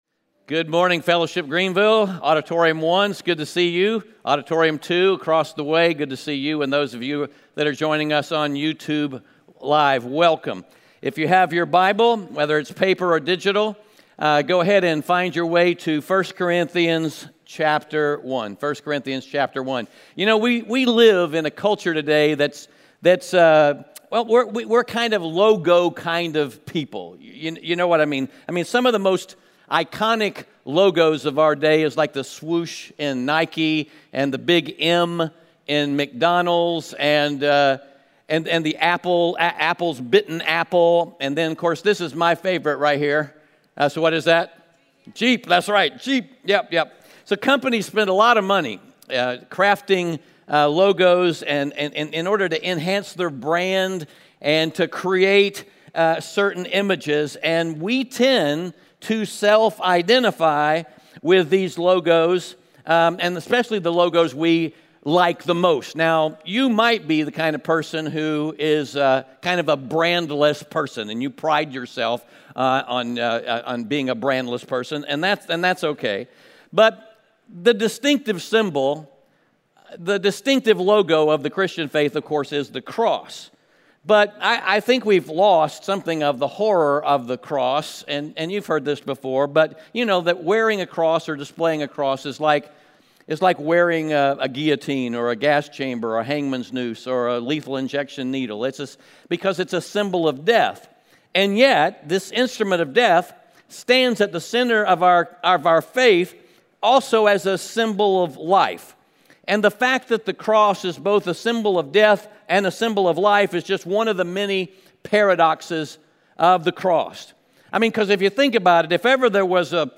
Audio Sermon Notes (PDF) Ask a Question In our culture today, we tend to be logo kind of people.